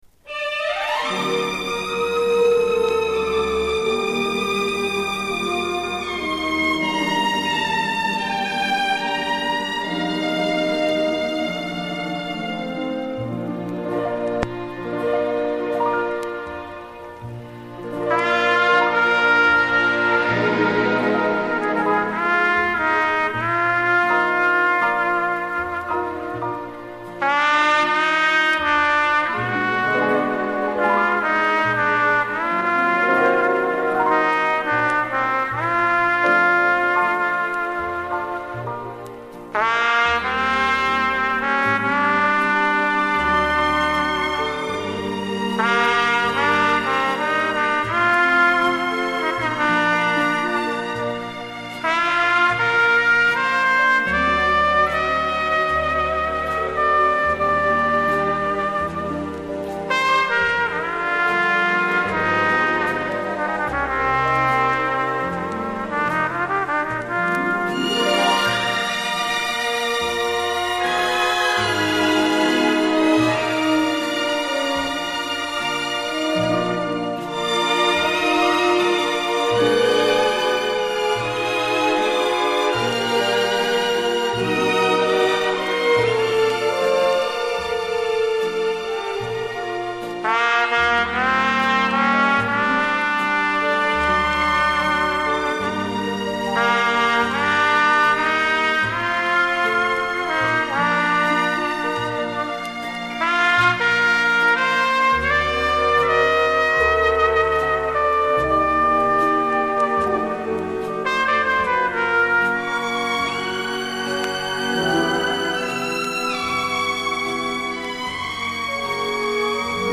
в стиле "ballada" со струнным оркестром